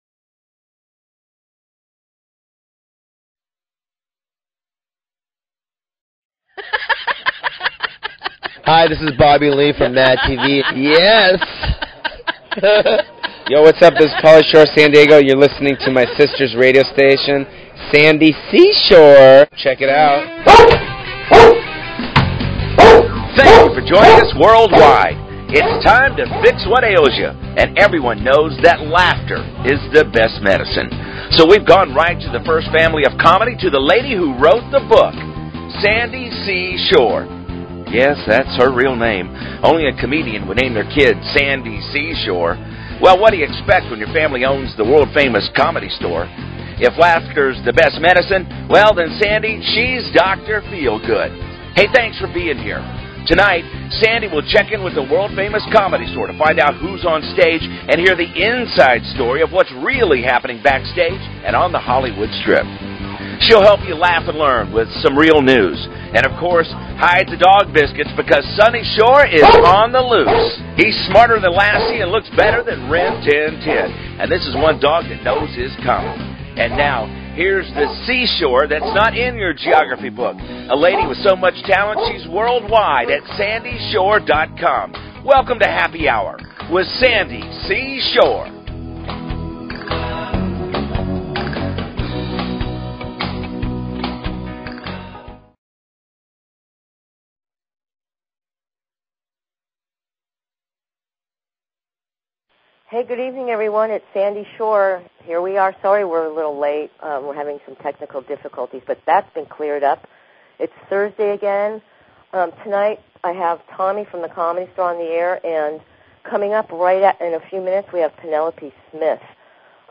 Talk Show Episode, Audio Podcast, Happy_Hour_Radio and Courtesy of BBS Radio on , show guests , about , categorized as